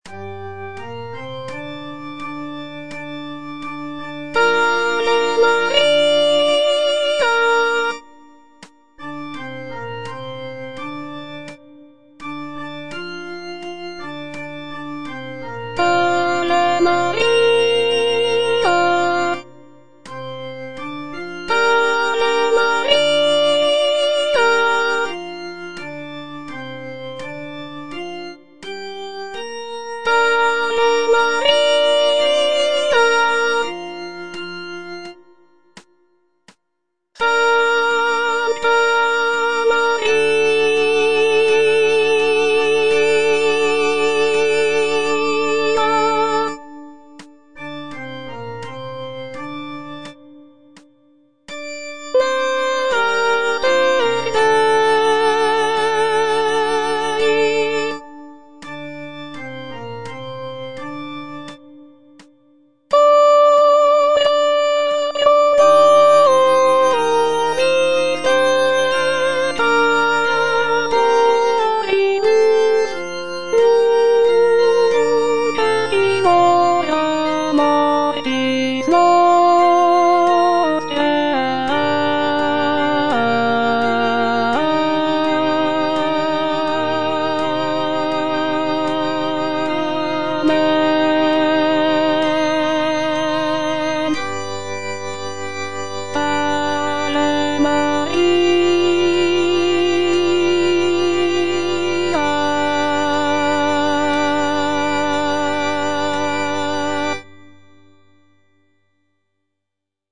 Mezzosoprano (Voice with metronome) Ads stop